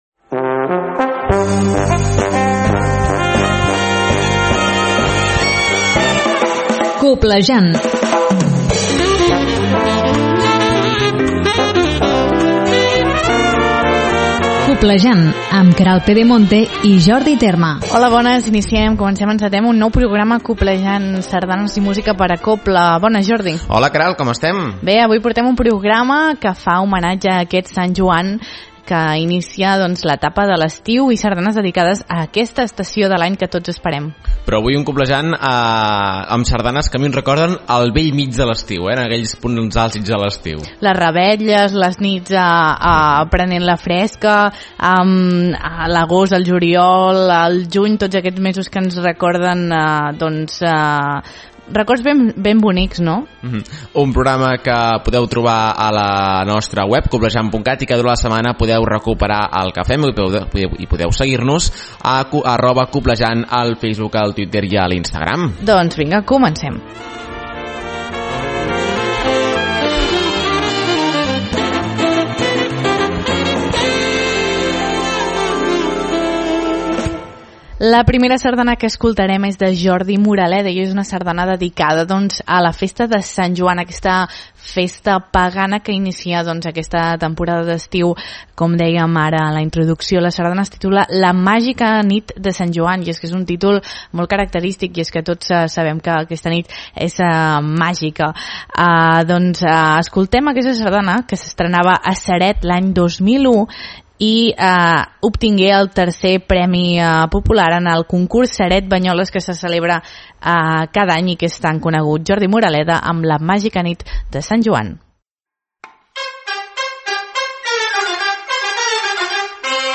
Aquesta setmana hem entrat a l’estiu i per aquest motiu dediquem el programa a aquesta estació de l’any i també amb la festa que l’inicia com és Sant Joan. Escoltarem sardanes de compositors com Eduard Martí Teixidó o Enric Morera amb sardanes que porten per noms moments de l’estiu.